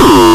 VEC3 Bassdrums Dirty 12.wav